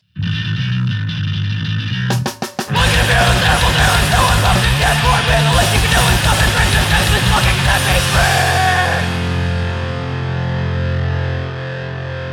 Loud and heavy music
Punk Rock Music